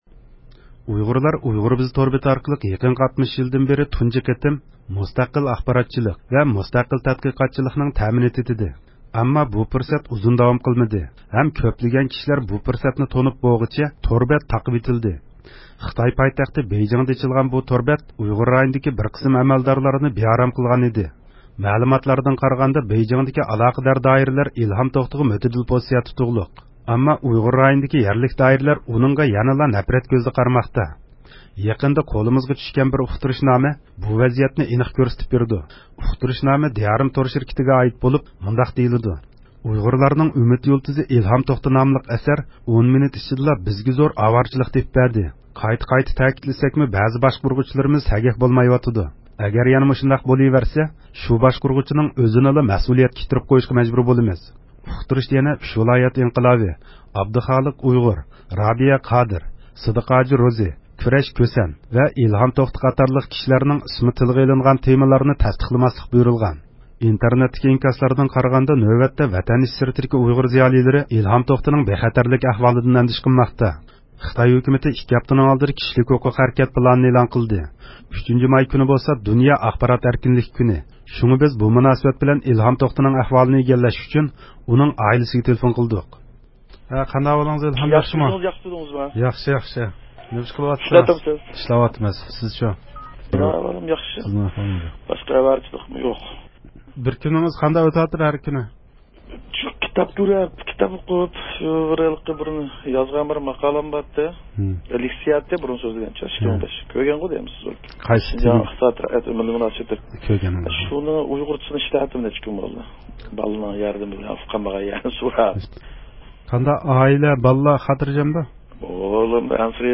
شۇڭا بىز بۇ مۇناسىۋەت بىلەن ئىلھام توختىنىڭ ئەھۋالىنى ئىگىلەش ئۈچۈن ئۇنىڭ ئائىلىسىگە تېلېفون قىلدۇق.